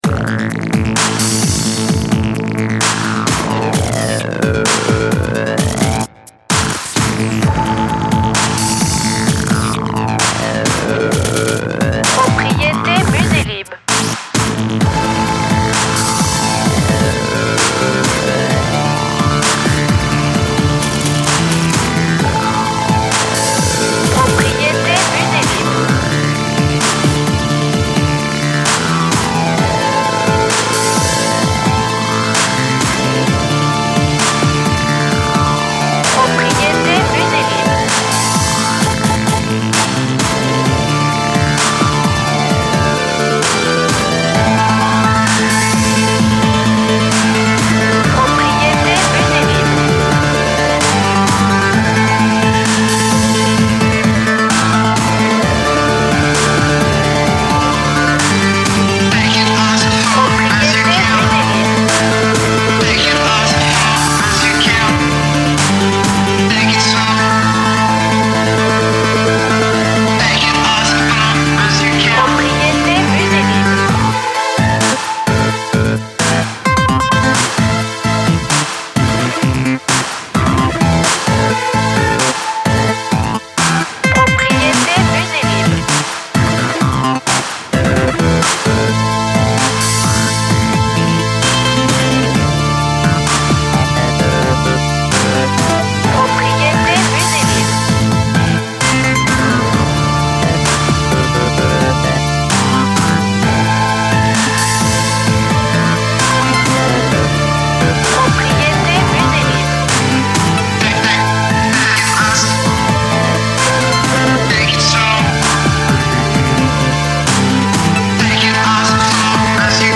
Dynamique, jeunesse, robotique, sport, explosif, moderne
BPM Moyen